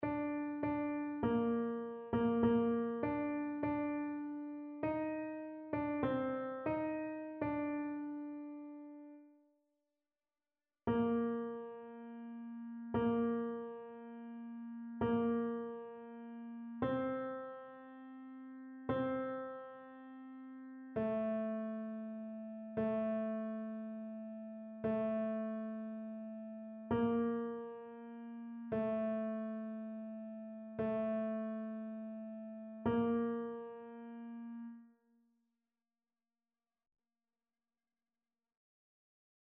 TénorBasse